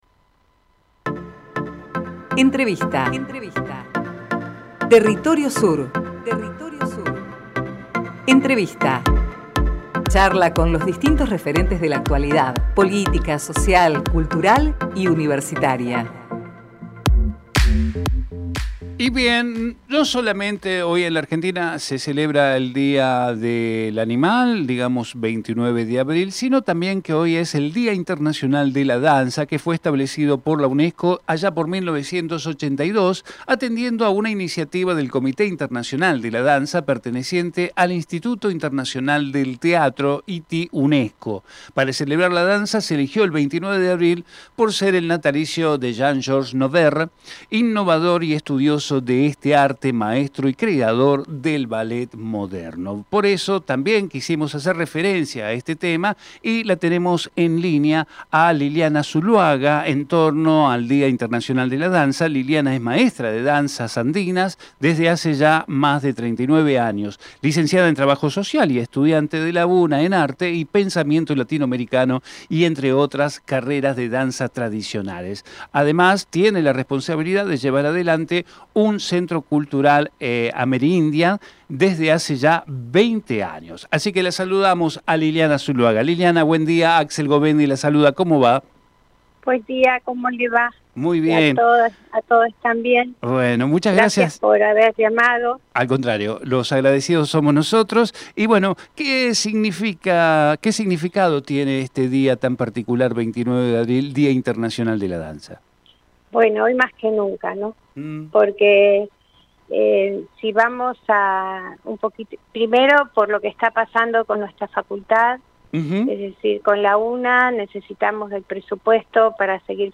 Compartimos con ustedes la entrevista